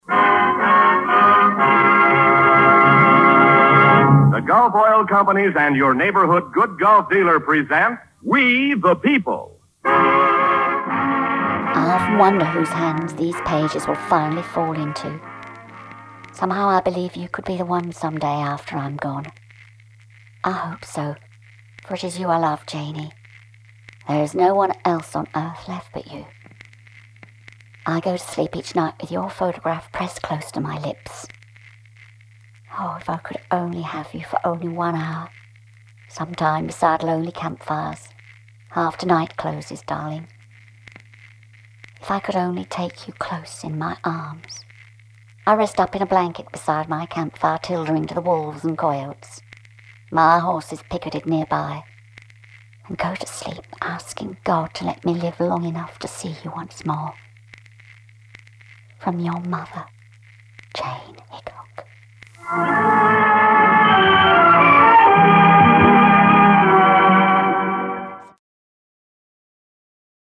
La fille de Calamity Jane a la radio en 1942 lisant les lettres de sa mere
- Soprano